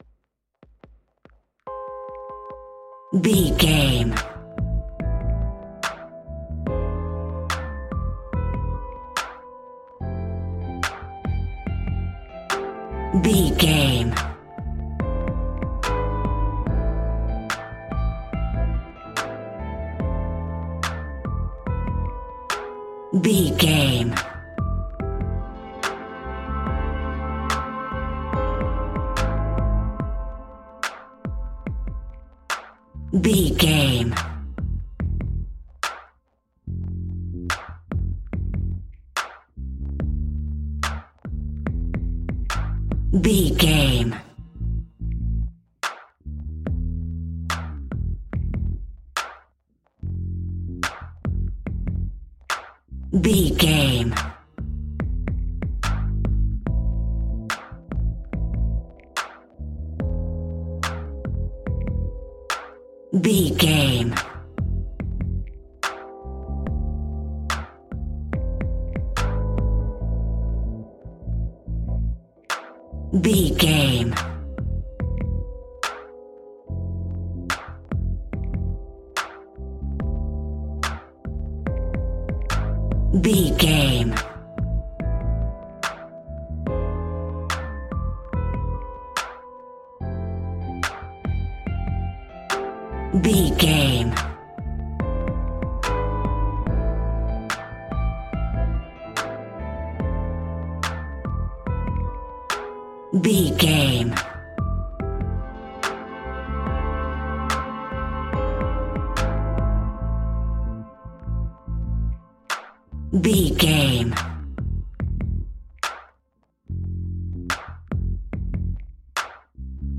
Epic / Action
Uplifting
Ionian/Major
F♯
hip hop